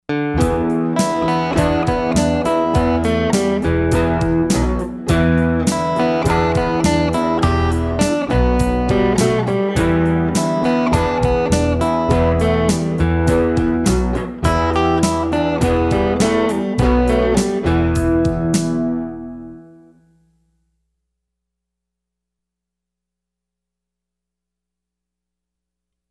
Rock Freebies